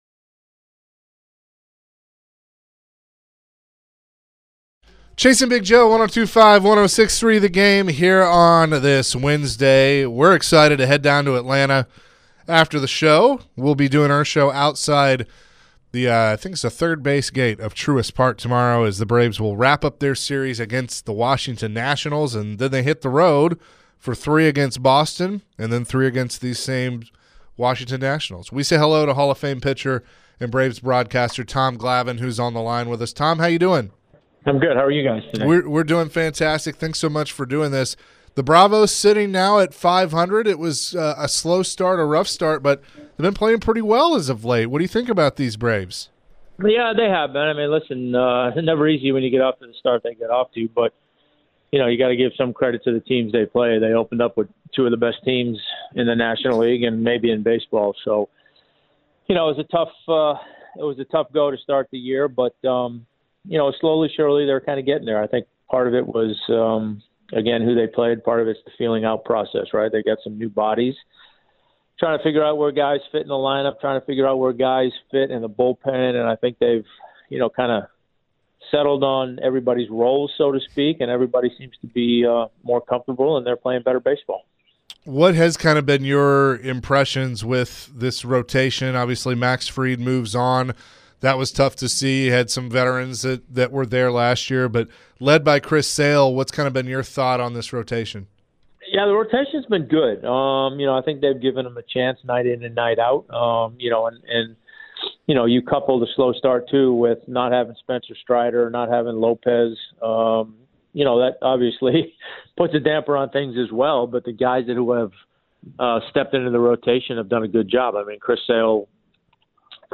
MLB Hall of Fame Pitcher Tom Glavine joined the show and shared his thoughts on the current Atlanta Braves team.